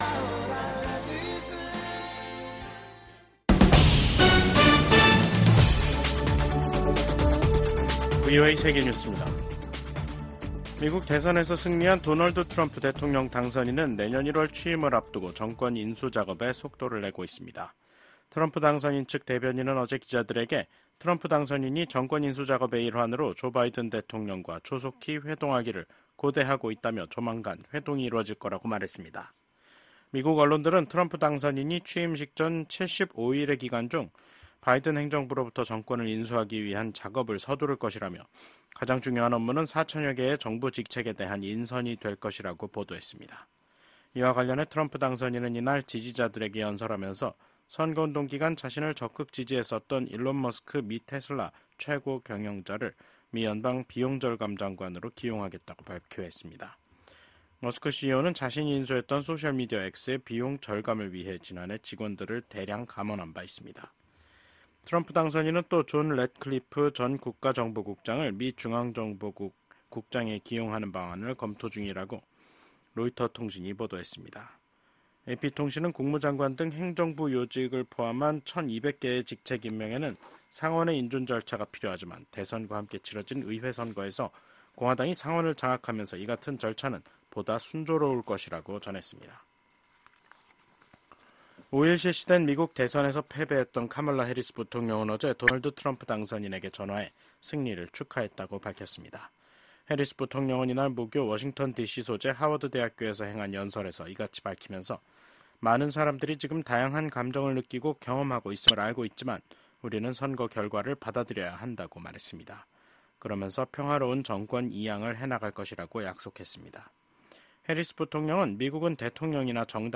VOA 한국어 간판 뉴스 프로그램 '뉴스 투데이', 2024년 11월 7일 3부 방송입니다. 제47대 대통령 선거에서 도널드 트럼프 전 대통령에게 패한 카멀라 해리스 부통령이 패배를 공개적으로 인정했습니다. 윤석열 한국 대통령은 도널드 트럼프 미국 대통령 당선인과 전화통화를 하고 양국의 긴밀한 협력관계 유지에 공감했습니다. 도널드 트럼프 대통령 당선인이 2기 행정부에서 첫 임기 때와 다른 대북 접근법을 보일 수도 있을 것으로 전문가들은 전망했습니다.